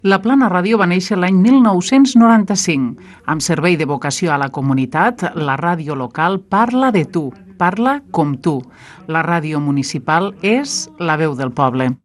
Locució enregistrada amb motiu del Dia mundial de la ràdio 2022.